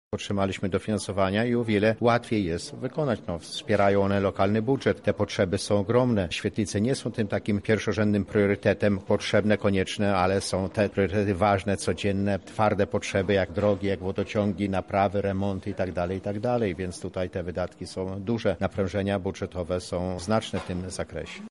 -mówi Wiesław Kociuba, Wójt Gminy Chełm.